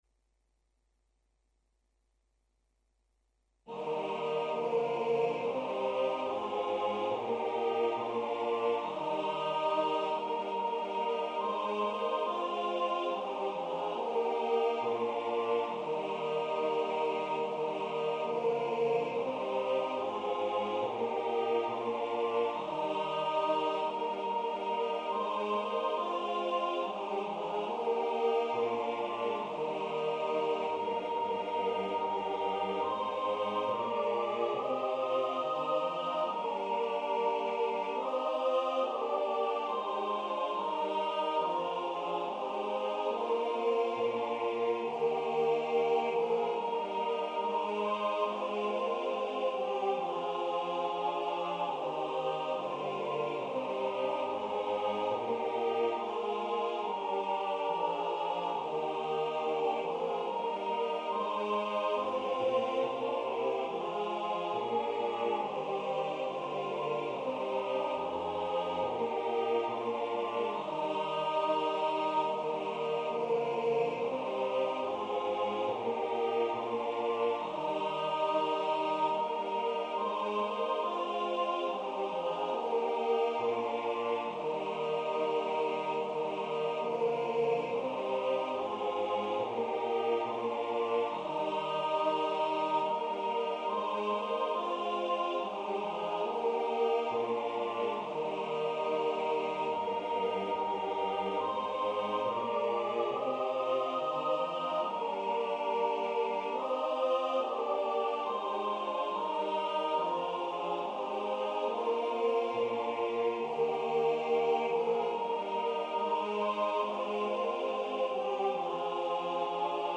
I was browsing round for a choir arrangement of the Chanuka song Maoz Tsur also spelled Maoz Tzur.
They havent learned to sing the lyrics yet.
maoz-tsur-satb.mp3